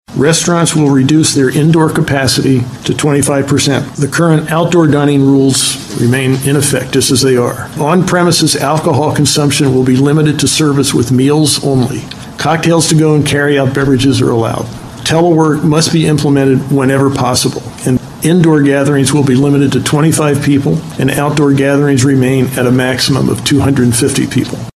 Pennsylvania Governor Tom Wolf addressed the media on Wednesday and imposed new restrictions to combat the spread of coronavirus in PA.